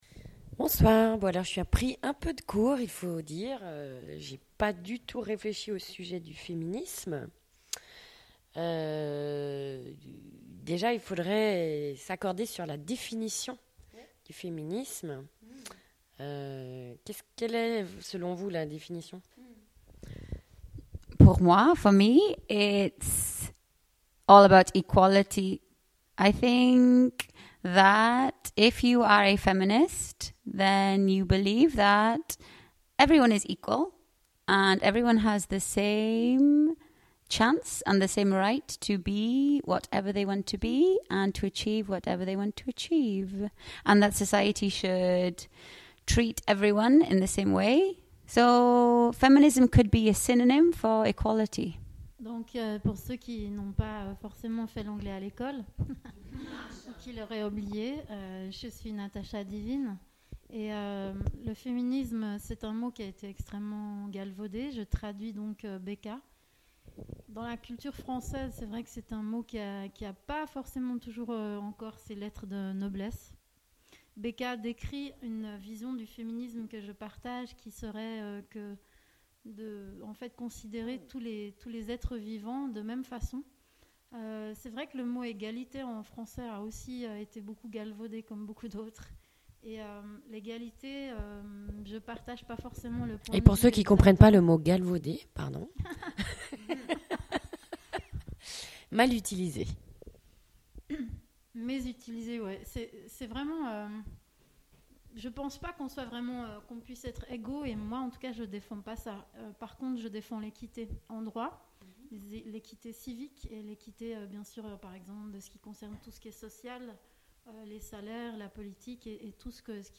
Emission discussion autour du Feminisme contemporain - français anglais